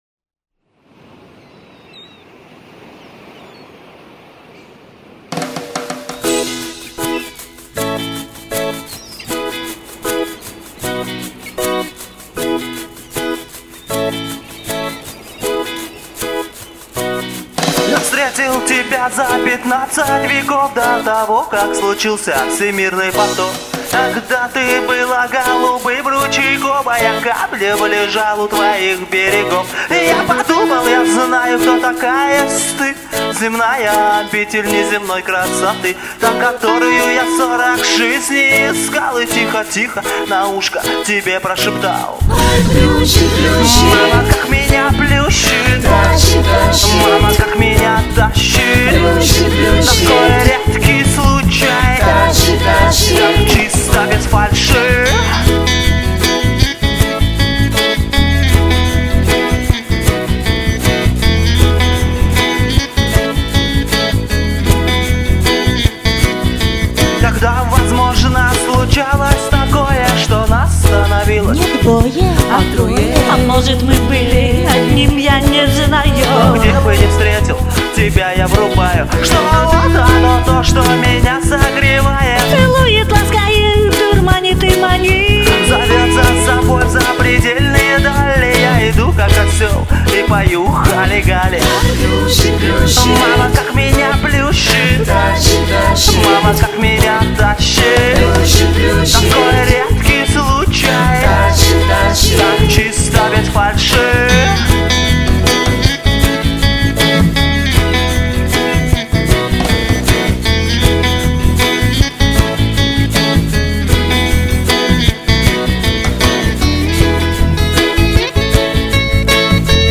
регги не может быть депрессивным априори